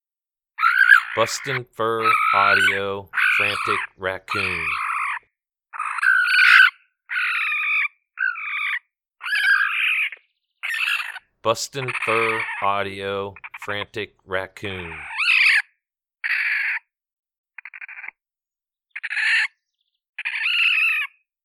Young Raccoon in distress.
BFA Frantic Raccoon Sample.mp3